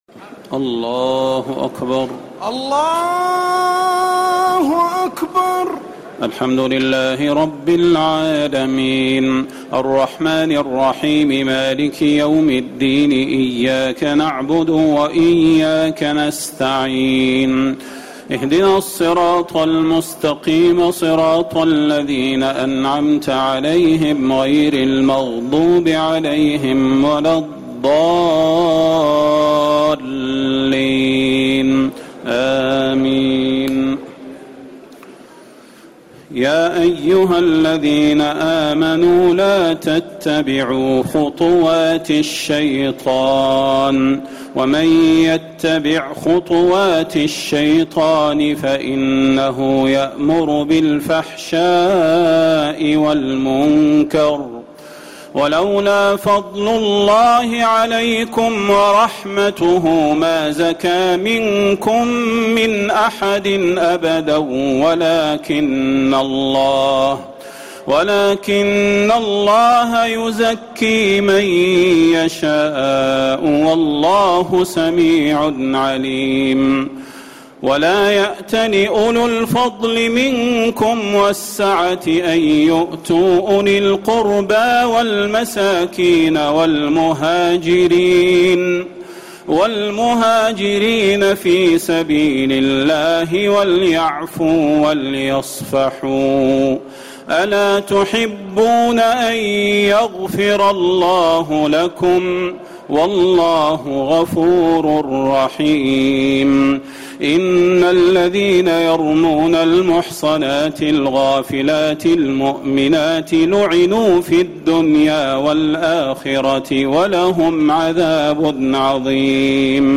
تراويح الليلة السابعة عشر رمضان 1439هـ من سورتي النور (21-64) و الفرقان (1-20) Taraweeh 17 st night Ramadan 1439H from Surah An-Noor and Al-Furqaan > تراويح الحرم النبوي عام 1439 🕌 > التراويح - تلاوات الحرمين